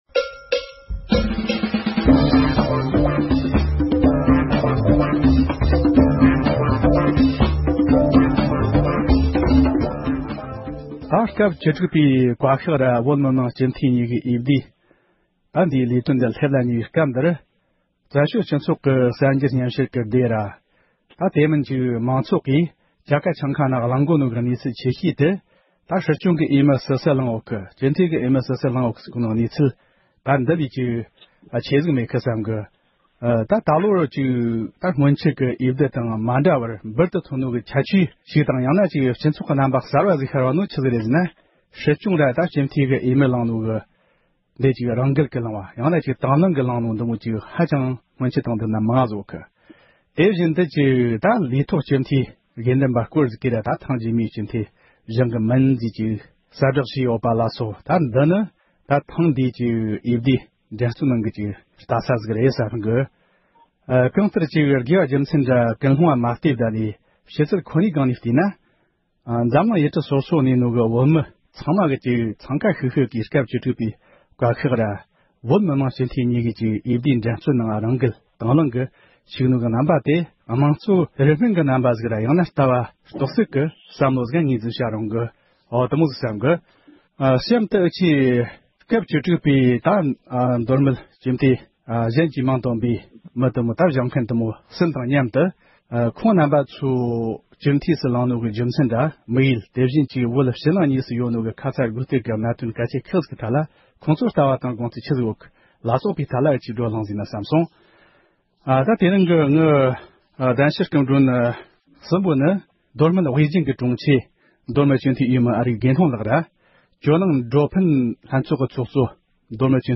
སྐབས་བཅུ་དྲུག་པའི་མདོ་སྨད་སྤྱི་འཐུས་འོས་མིར་བཞེངས་མཁན་ཁག་ཅིག་དང་ལྷན་དུ། ཁོང་རྣམ་པ་ཚོ་སྤྱི་འཐུས་སུ་ལངས་པའི་རྒྱུ་མཚན་སོགས་ཀྱི་སྐོར་གླེང་བ།